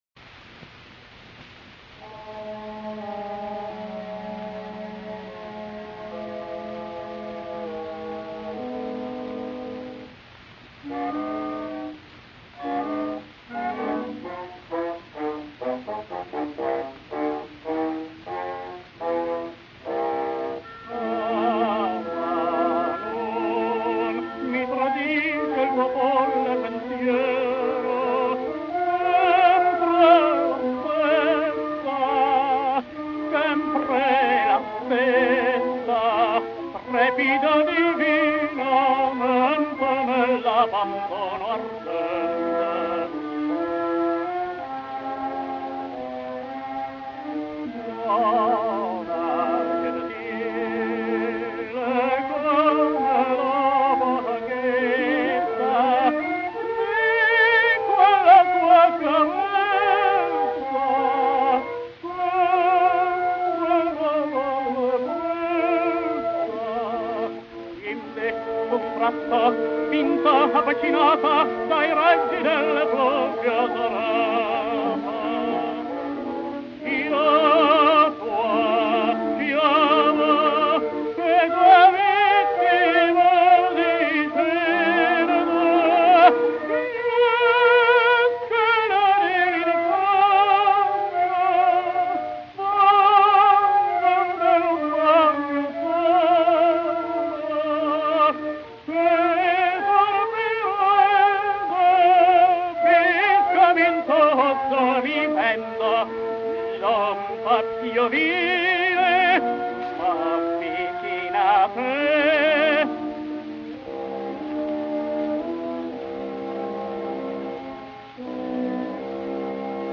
tenore